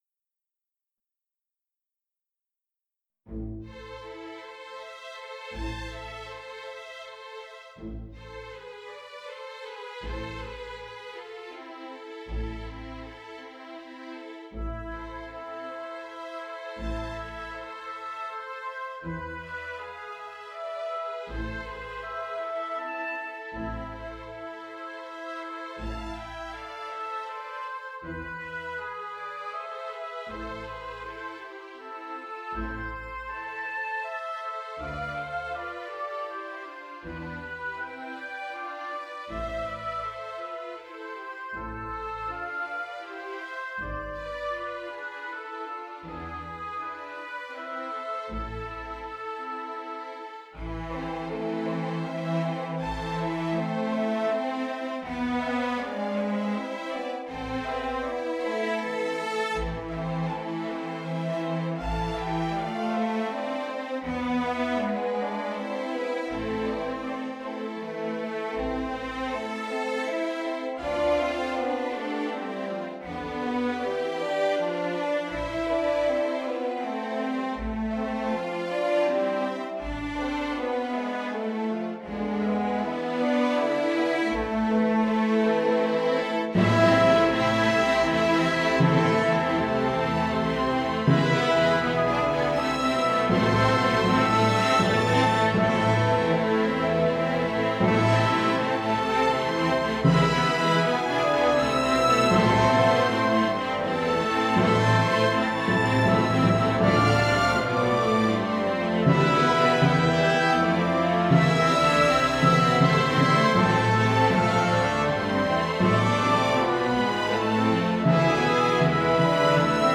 A few days ago I was sent this beautful melancholic melody sketch by a composer and I was asked whether I could arrange it to an orchestral piece?
As to what I think: a pleasant "cobbler's patch of a melody" (Cf. Beethoven on the Diabelli theme) with a simple and repetitive tonic-dominant harmonisation (I-V) with one subdominant chord (IV) - not much else you could have done with it, IMO. In terms of orchestration: solid and classical.